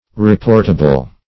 Reportable \Re*port"a*ble\ (-[.a]*b'l), a.